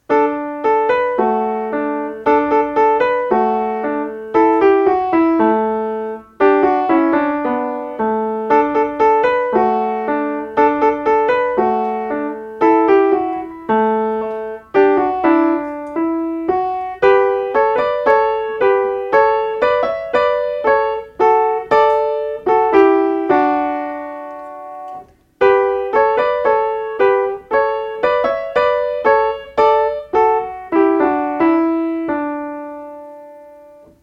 ensemble
La_colline_aux_corallines_ensemble.mp3